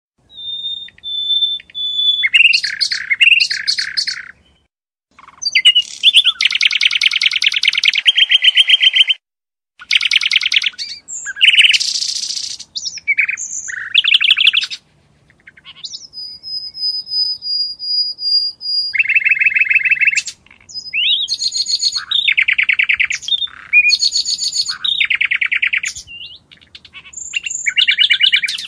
Naturljud, Fågelsång, Djur